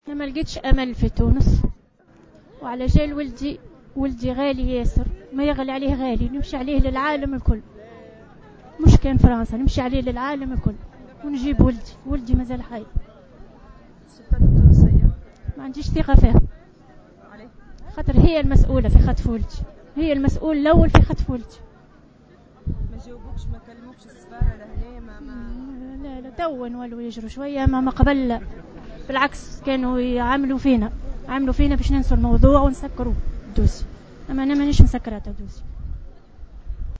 انتظمت اليوم في ساحة الجمهورية بالعاصمة الفرنسية باريس وقفة احتجاجية